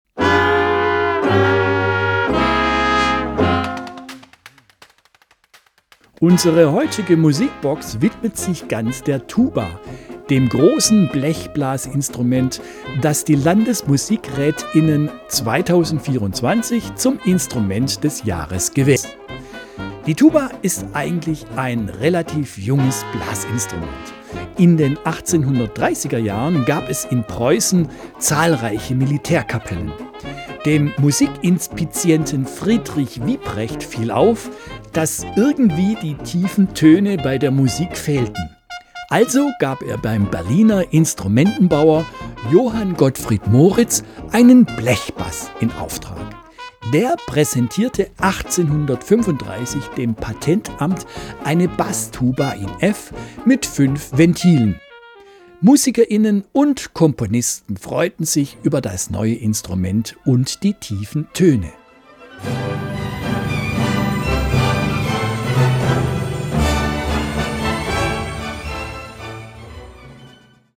Unsere heutige Musikbox widmet sich ganz der Tuba, dem großen Blechblasinstrument, das die Landesmusikrät*innen 2024 zum Instrument des Jahres gewählt haben. In den nächsten 60 Minuten spielen wir denn auch viel Tuba-Musik aus Klassik und Jazz.